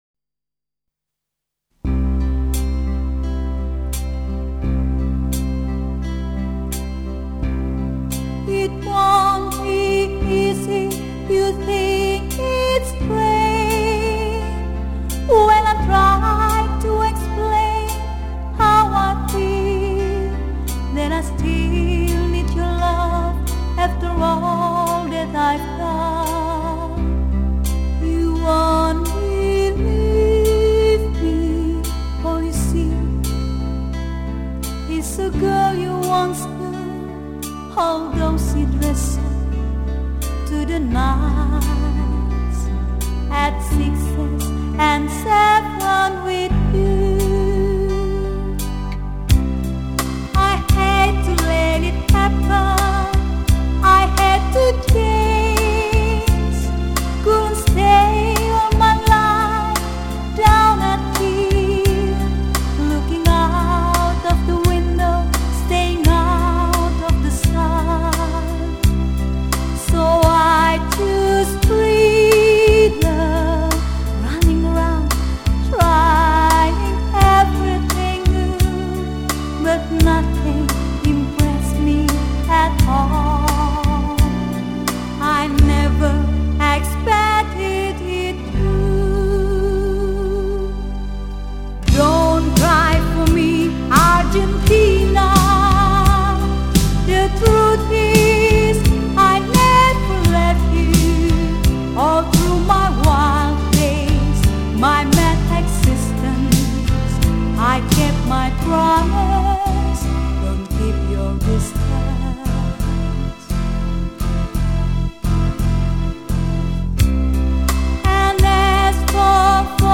最辉煌灿烂的英文经典曲，超值珍藏版！